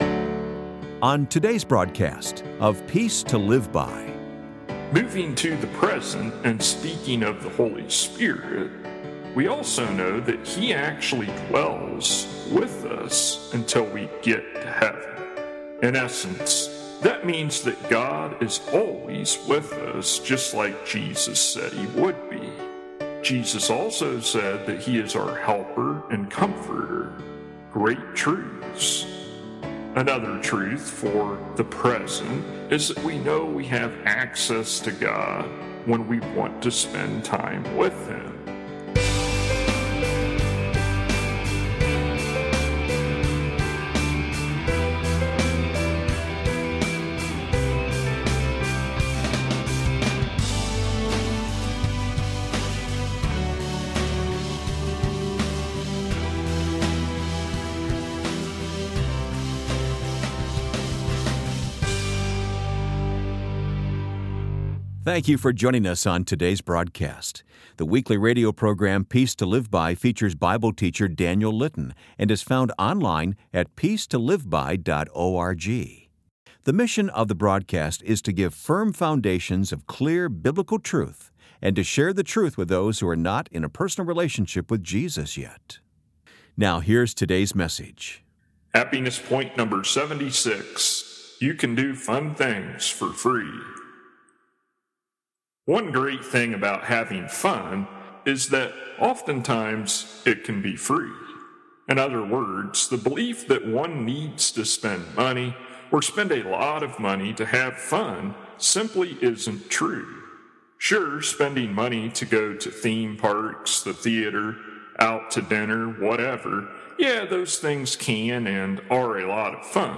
[Transcript may not match broadcasted sermon word for word]